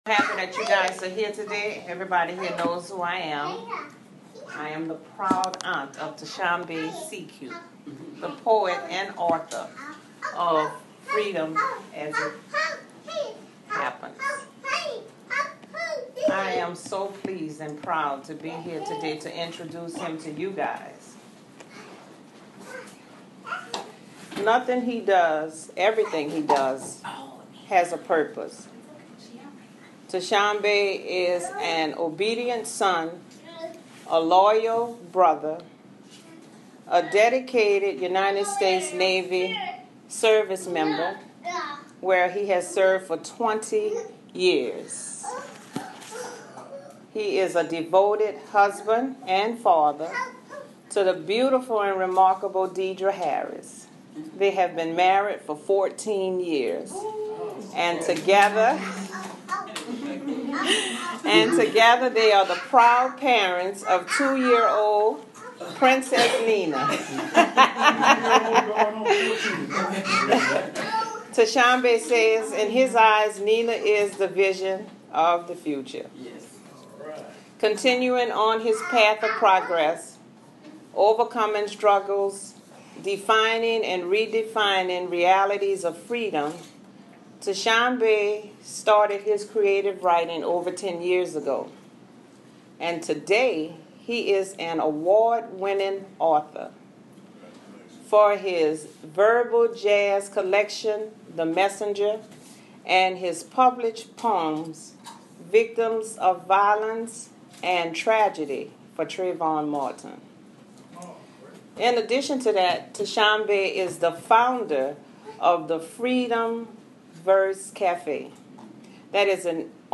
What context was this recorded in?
Book Reading Freedom As It Happens | Baton Rouge book-reading-signing-baton-rouge.mp3